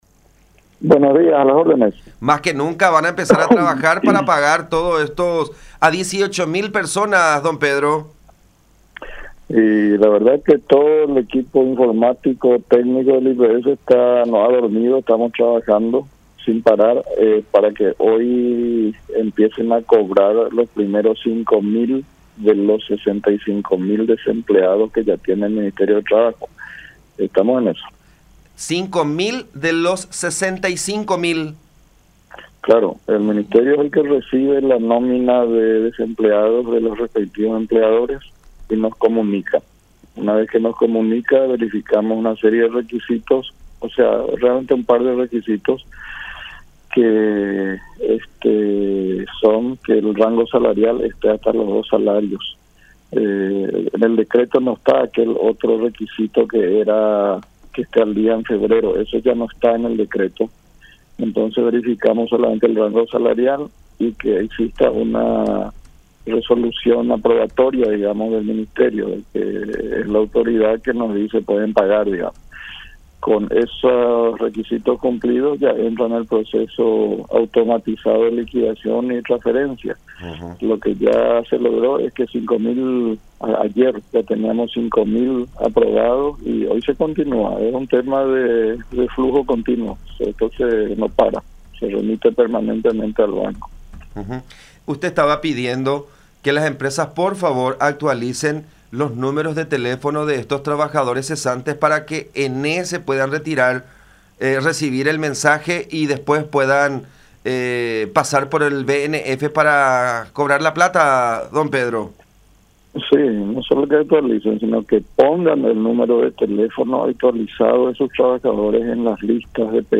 en diálogo con La Unión.